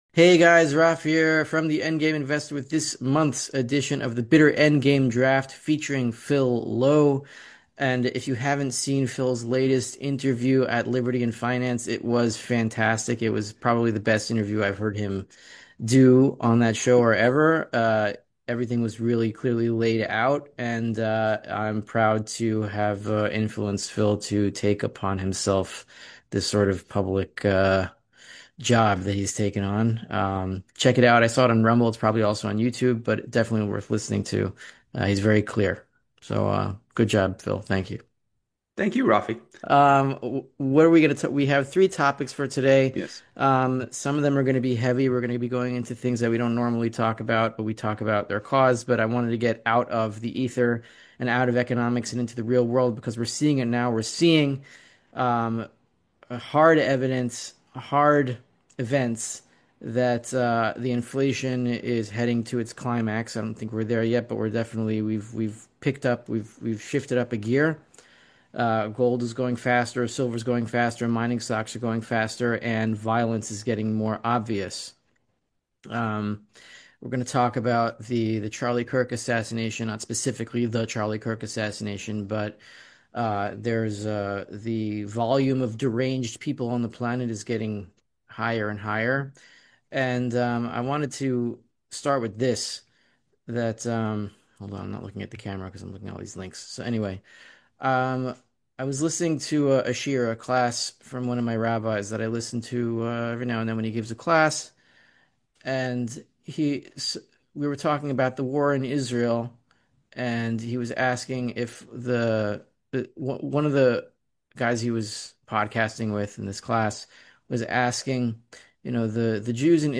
They highlight how economic troubles affect everyone, from workers to those on social benefits. As inflation worsens, they predict more social issues. The conversation also touches on the dangers of communism and the importance of personal safety.